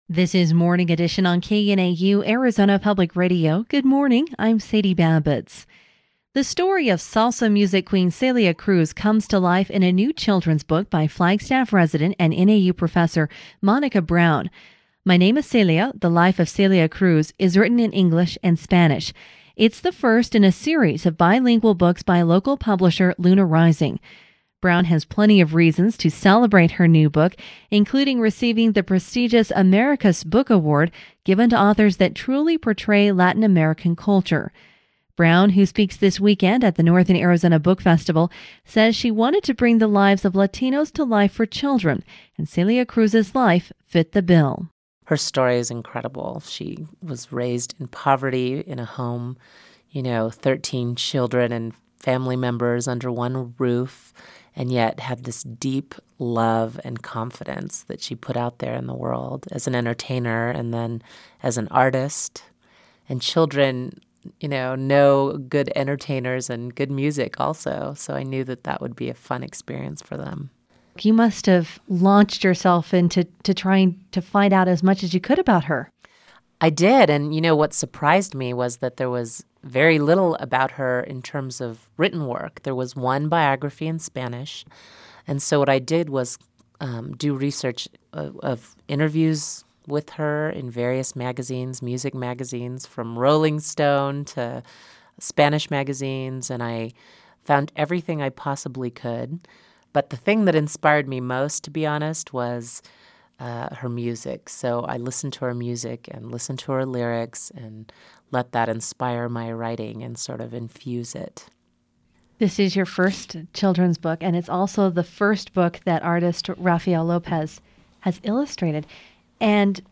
interviewapril2005.mp3